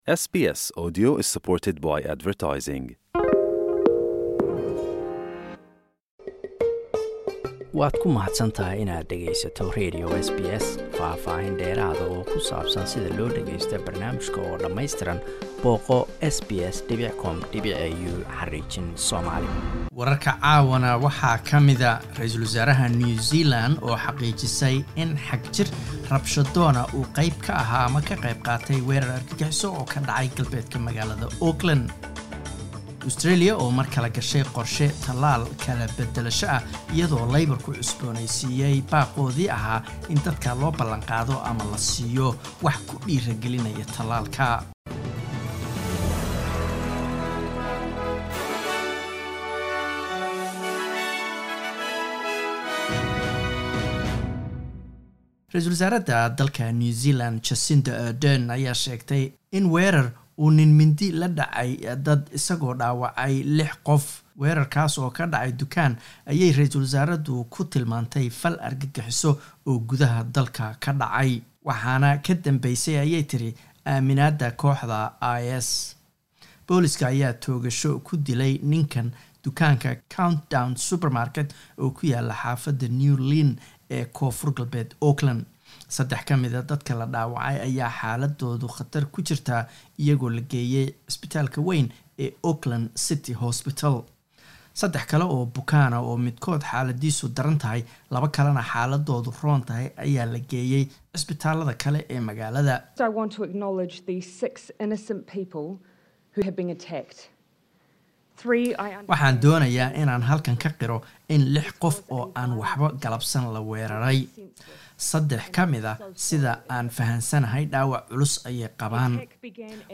Warar SBS Somali Jimco 03 Sebtember